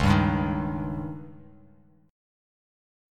C#sus2b5 chord